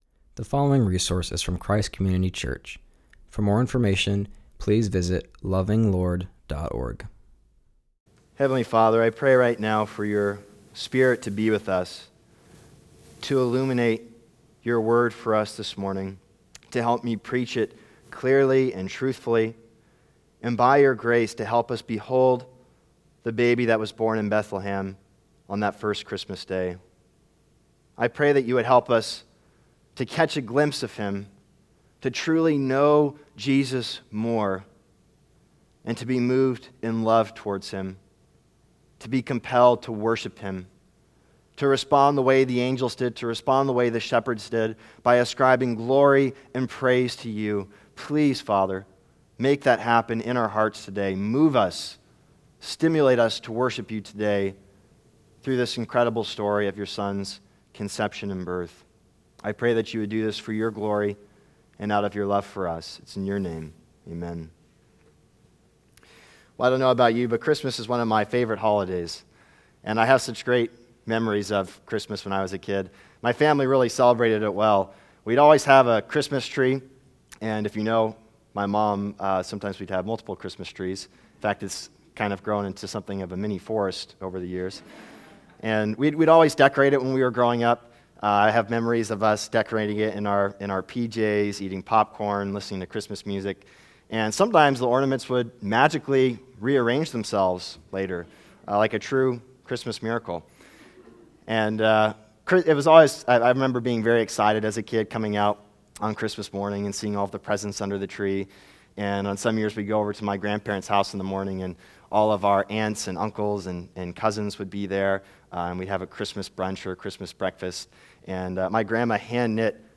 preaching on Luke 1:26-38; 2:1-20.